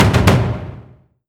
ruleset-select-taiko.wav